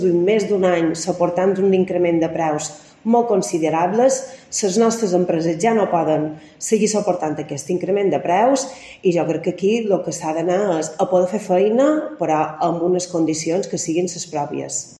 CORTE DE VOZ CONSTRUCTORES